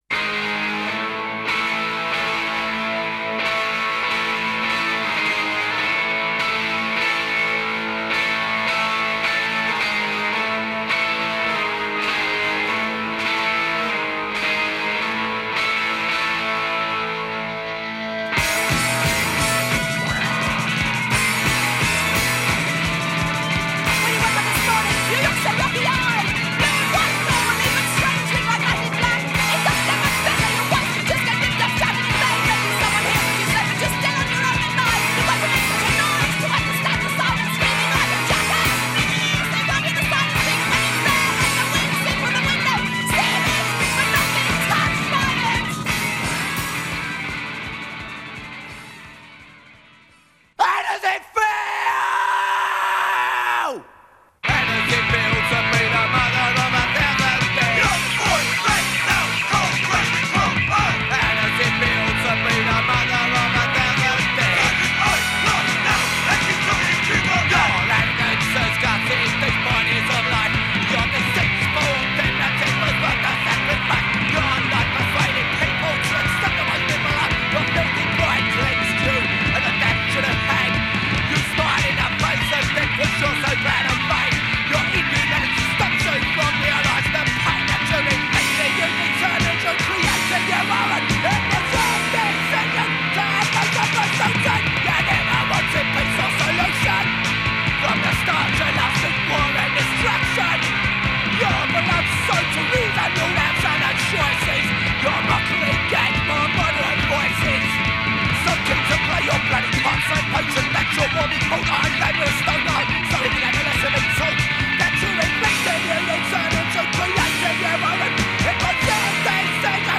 Full song in stereo